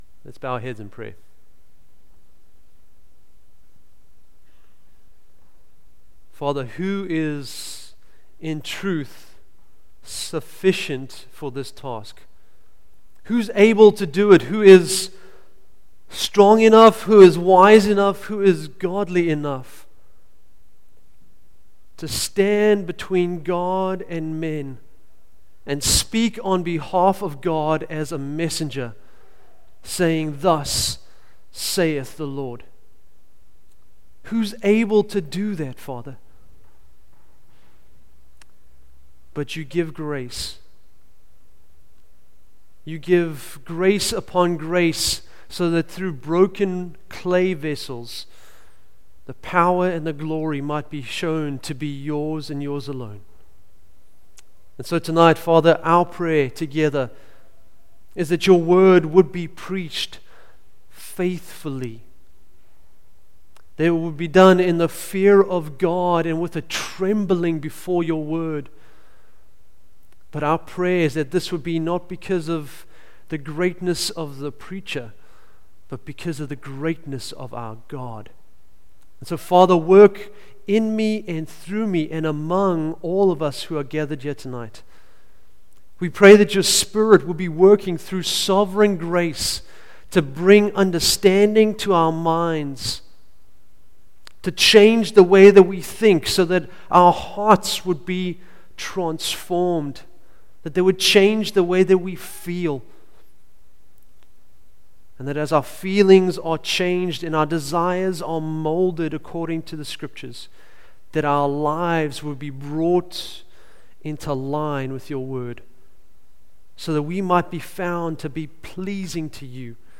Facebook Twitter email Posted in Evening Service